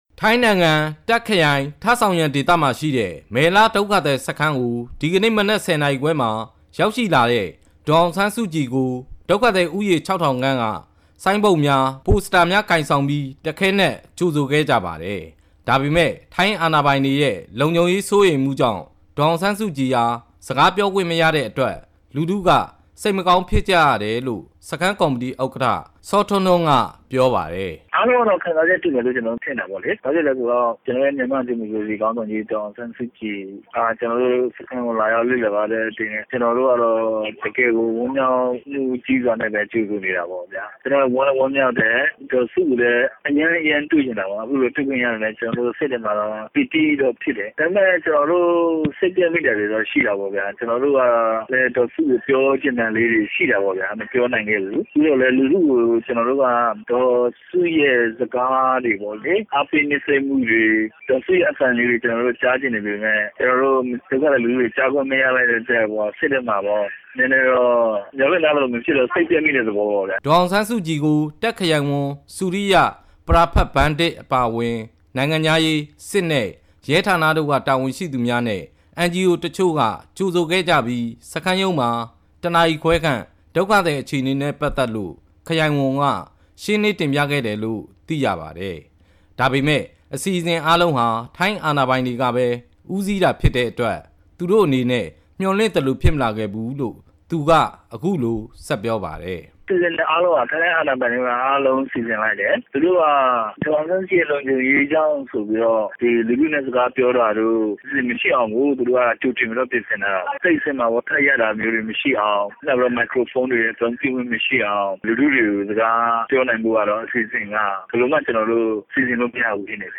ဒေါ်အောင်ဆန်းစုကြည်က ပလပ်စတပ် ထိုင်ခုံပေါ် မတ်တပ်ရပ်ပြီး အသံချဲ့စက် မိုက်ကရိုဖုန်းမပါဘဲ သူ့ကို ကြိုဆိုနေကြတဲ့ လူအုပ်ကြီးကို နှုတ်ဆက်စကား ပြောကြားပါတယ်။
ဒေါ်အောင်ဆန်းစုကြည် အနေနဲ့ မိုက်ကရိုဖုန်း မရှိတဲ့အတွက် သူ့ကိုကြိုဆိုကြတဲ့ လူအုပ်ကြီးကို ကြာကြာစကား မပြောနိုင်ခဲ့ပေမဲ့ ဒုက္ခသည်စခန်းက ခေါင်းဆောင်တွေနဲ့ အချိန်ယူပြီး ဆွေးနွေးခဲ့ပါတယ်။